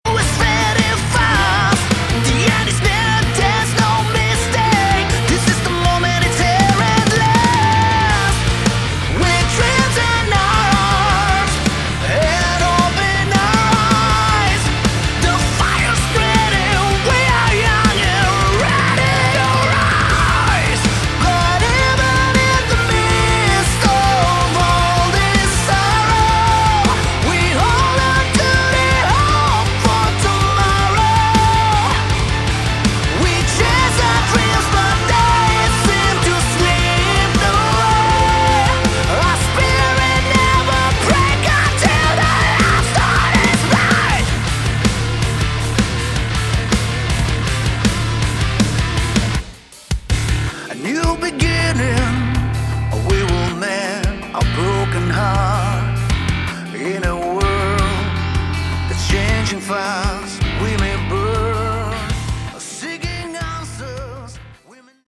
Category: Hard Rock
Vocals
Guitar
Keyboards
Bass
Drums